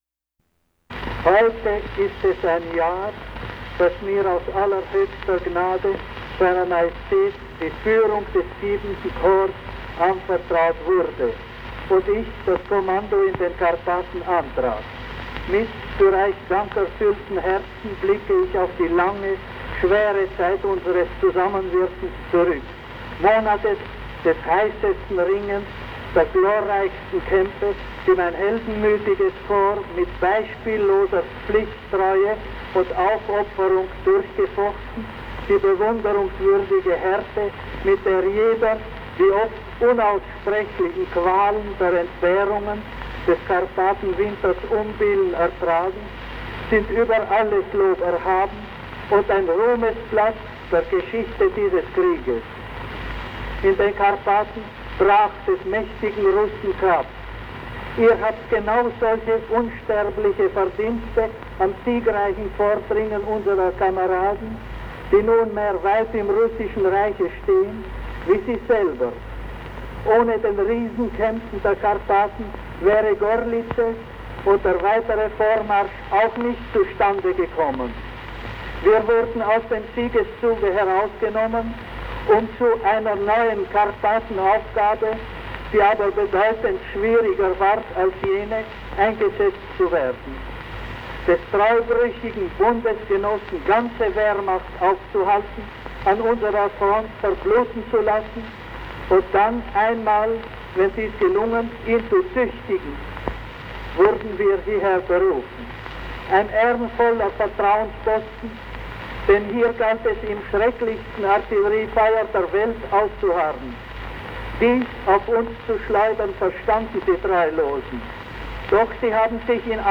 Charles I, Emperor of Austria, delivers the Order of the Day to his troops on the front during World War I.